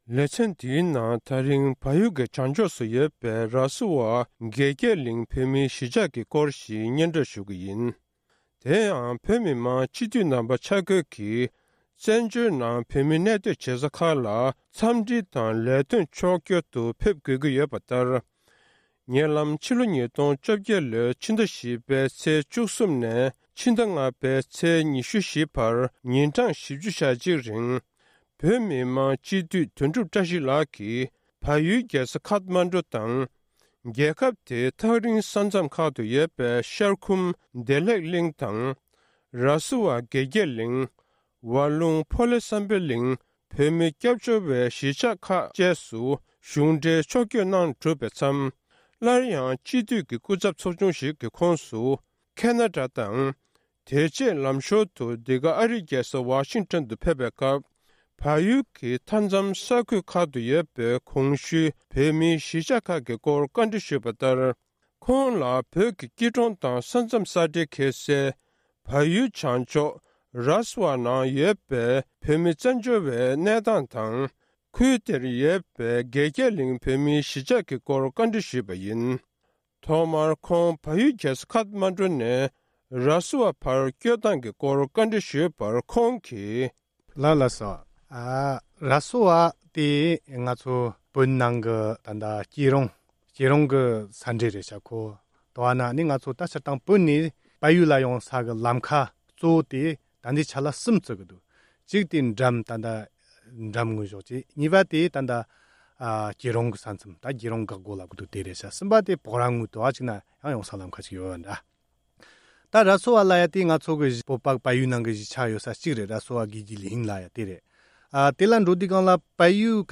གནས་འདྲི